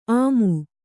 ♪ āmu